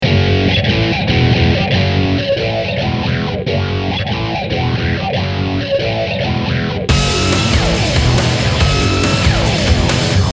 хз, мне как-то так слышится, сделал на стартовом звуке гитары, сначала она играет как есть (но по центру) а потом подмешиваю FilterFreak.